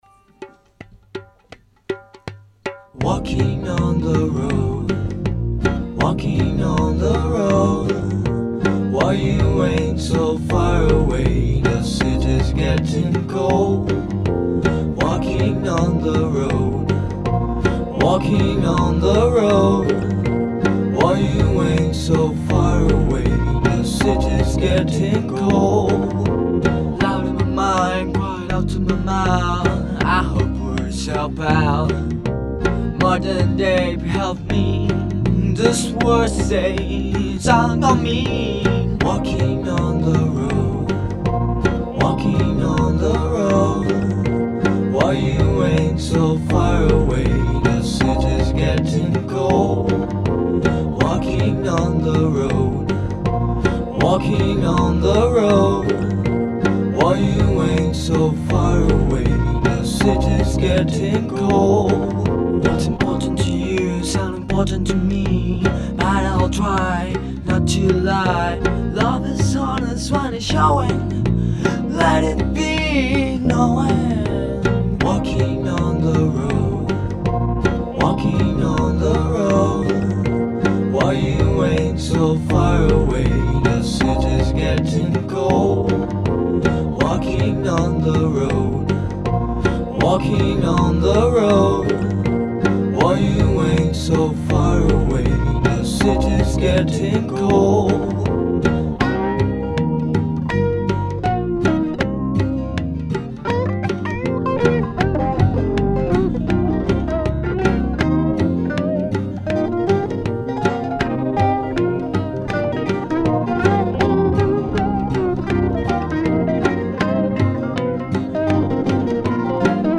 Psychedelic rock